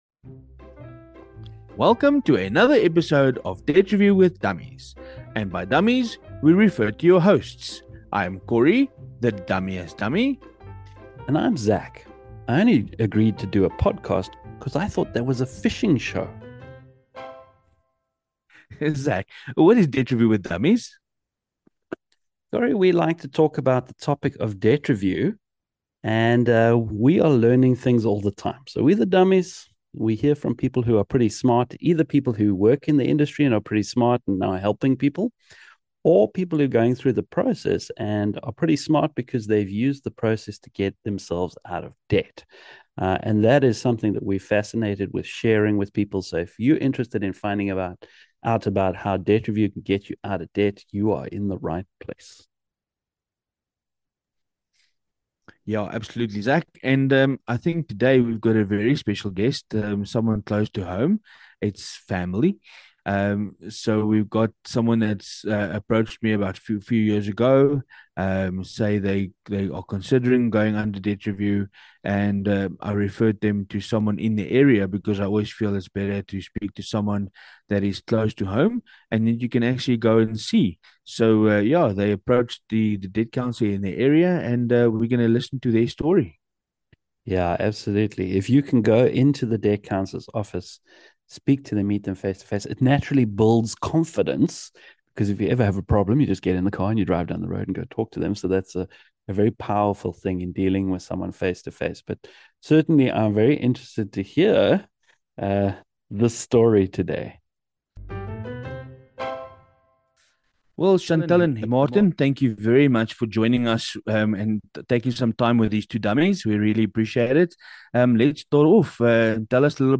The Dummies shed a few tears of sympathy as they spoke to a family who had a MASSIVE financial setback just when they least expected it.